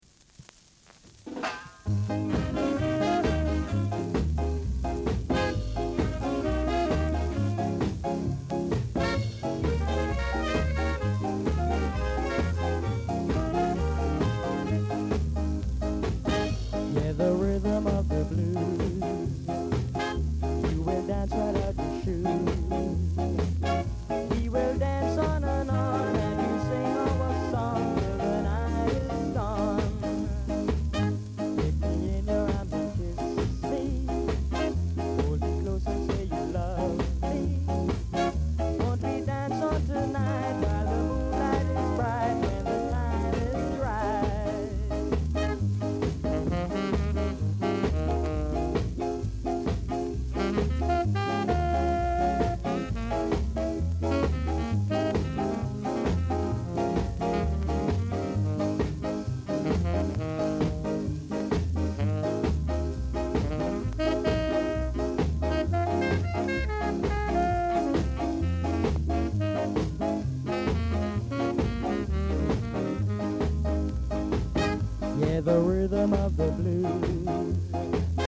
Ska Male Vocal
Very rare! great ska & soul vocal!